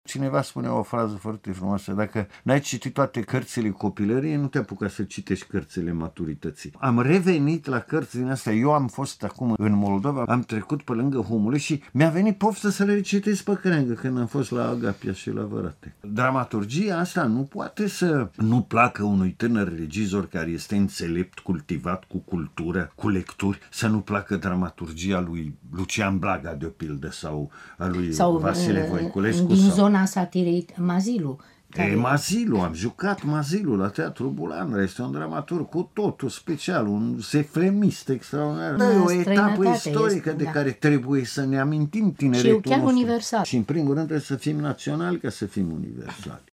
în care invitat a fost Ion Besoiu: